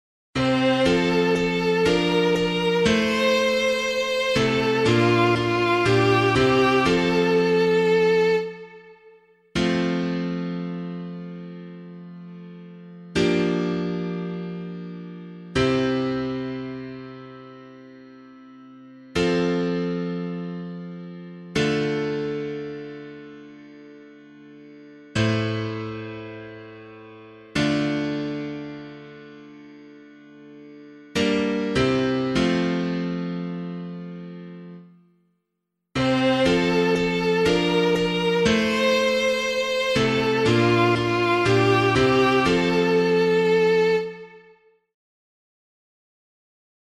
Responsorial Psalm     Psalm 89 (88): 2-3, 16-17, 18-19
B♭ major